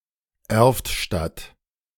Erftstadt (German pronunciation: [ˈɛʁftˌʃtat]
De-Erftstadt.ogg.mp3